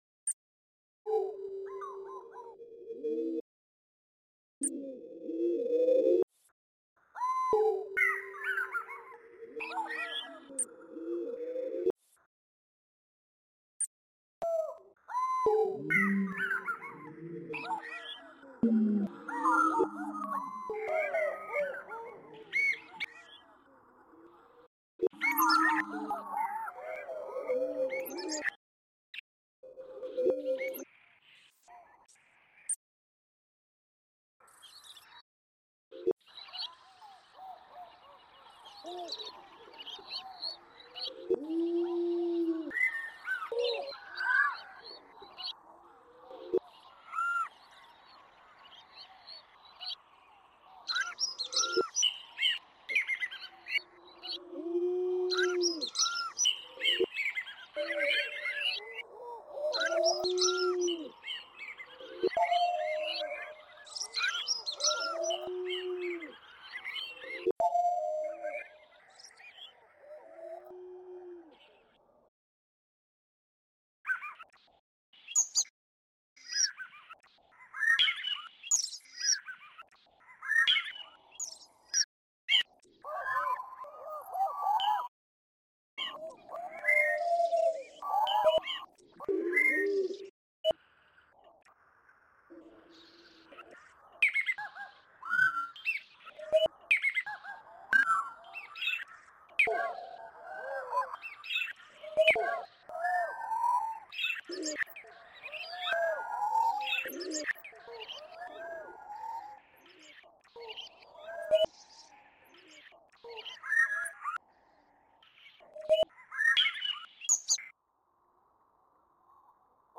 African fish eagles in Senegal reimagined